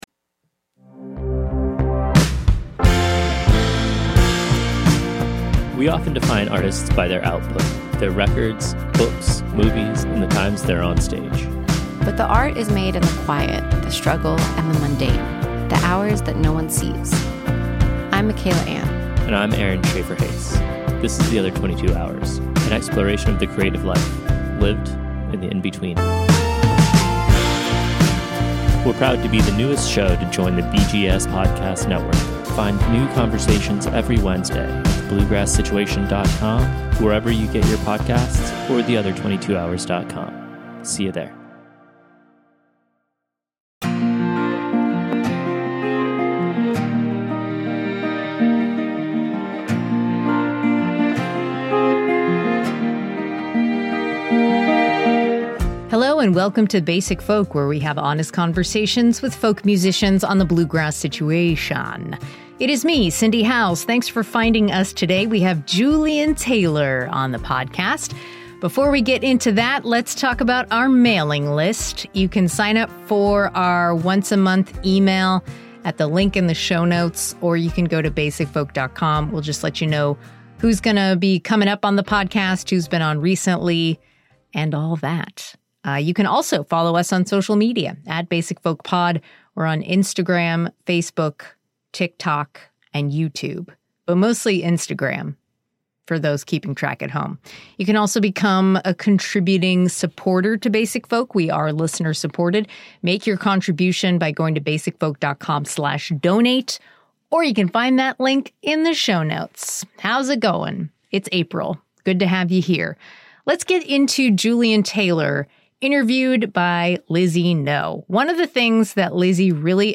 Recorded during the making of the film, podcast episodes will feature in-depth conversations with Americana legends, including Charlie Sexton, Buddy Miller, Mary Gauthier, and Williams herself.